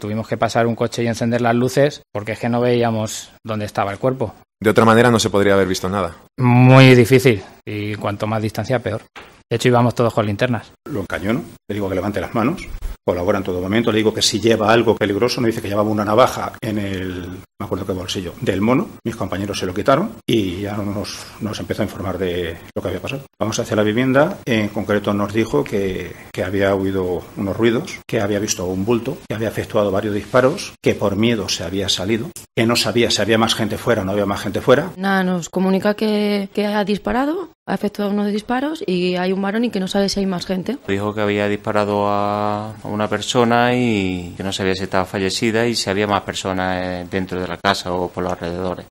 Policías que llegaron a la finca de La Atalaya donde ocurrieron los hechos